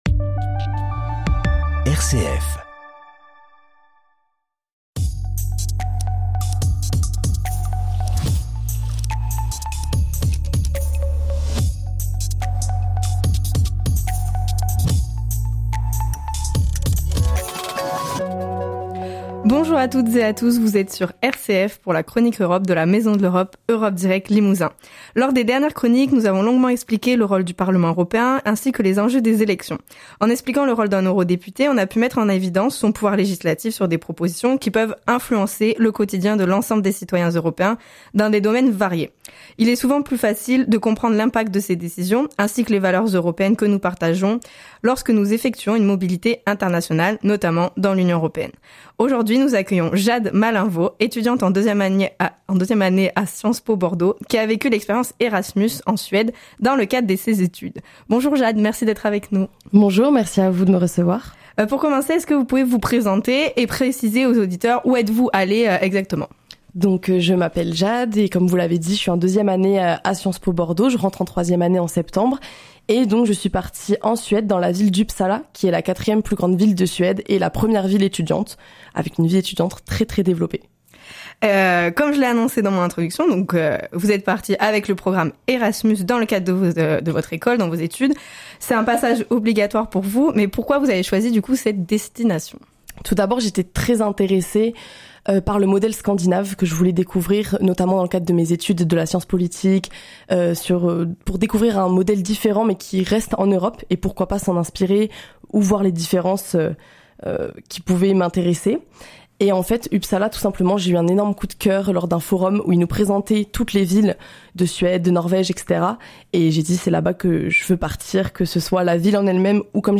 Témoignage : Erasmus+ études en Suède | Maison de L'Europe